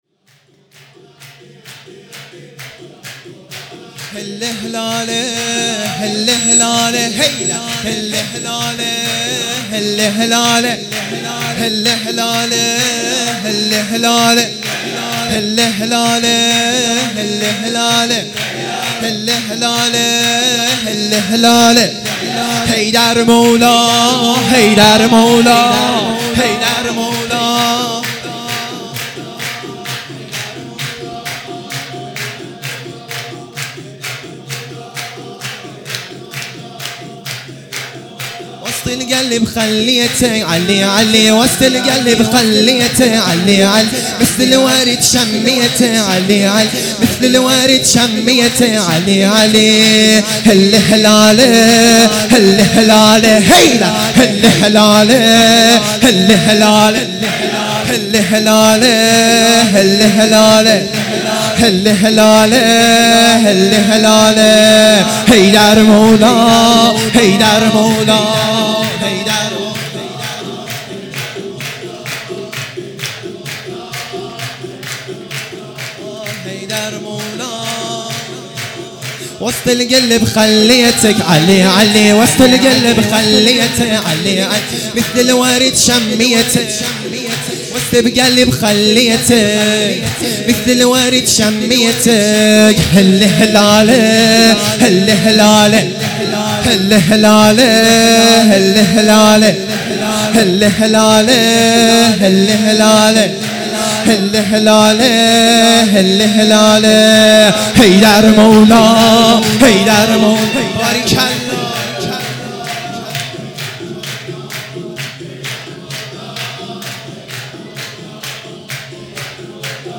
سرود عربی ا هل الهلاله